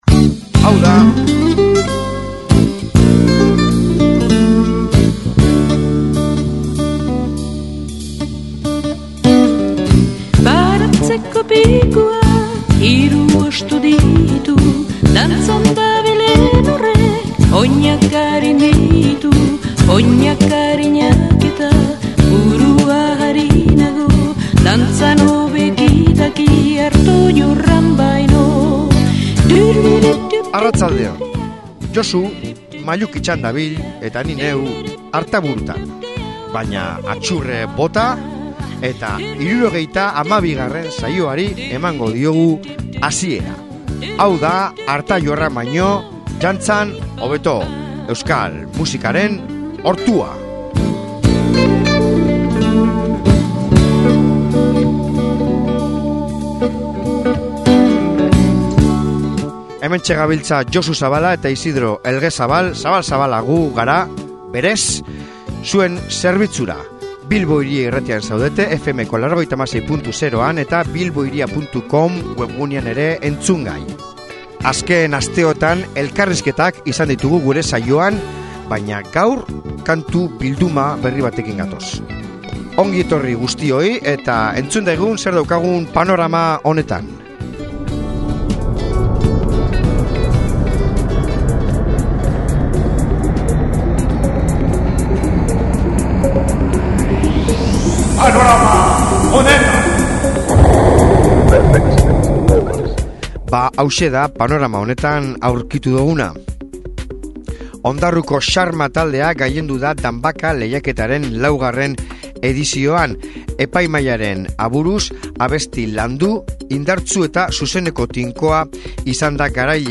jazz esperimentala